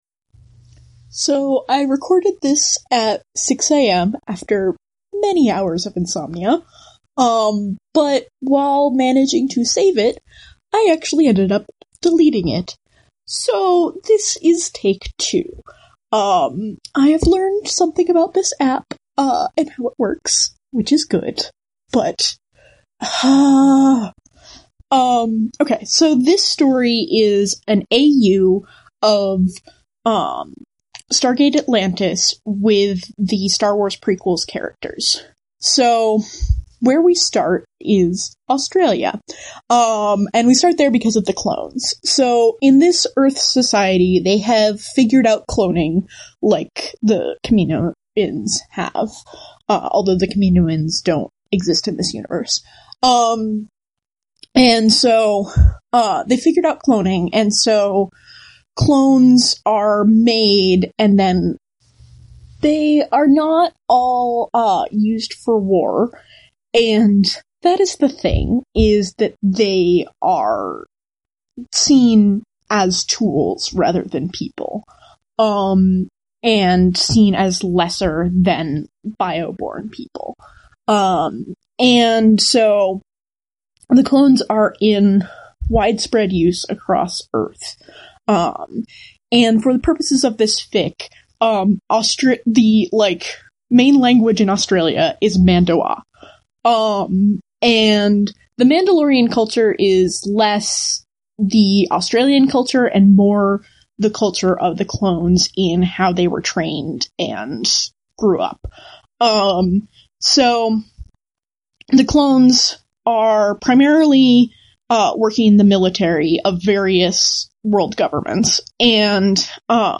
When I fall asleep at night I tell myself not!fics, this is one of them recorded in an oral not!fic!